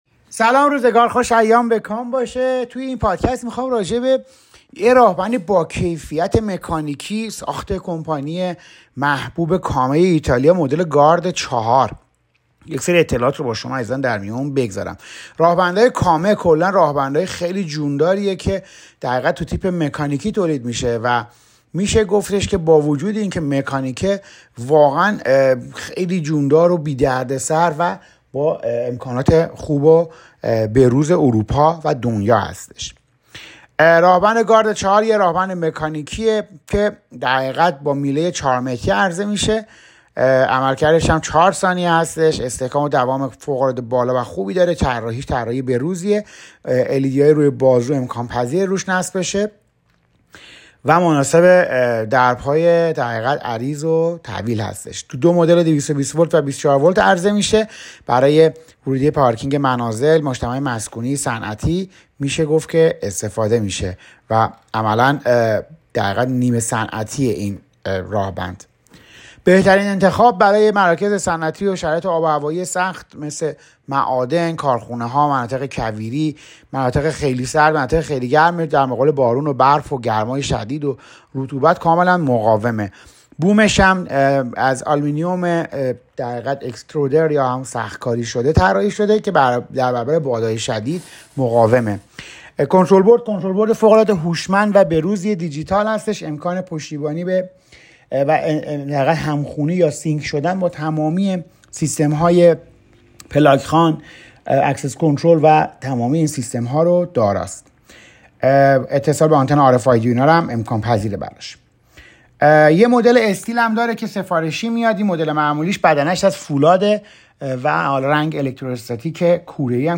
پادکست توضیحات محصول راهبند CAME ایتالیا گارد 4 متری